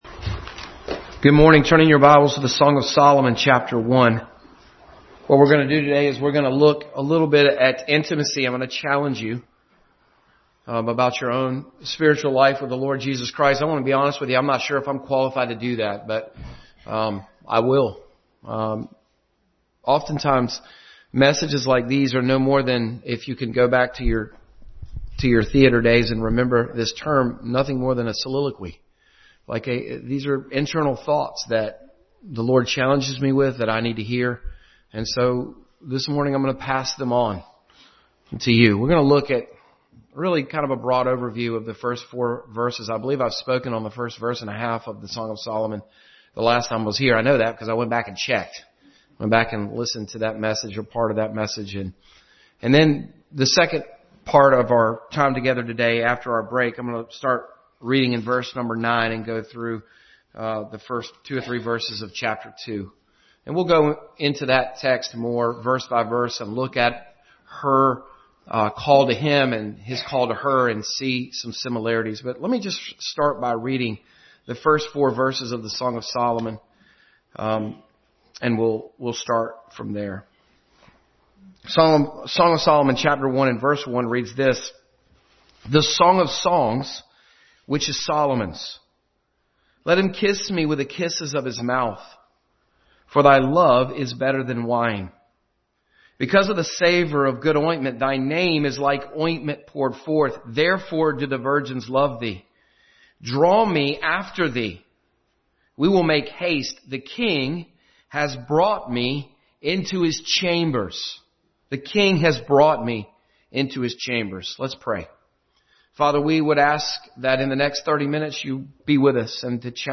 Intimacy with God Passage: Song of Solomon 1:1-4, Exodus 5:1, Revelation 1:13 Service Type: Sunday School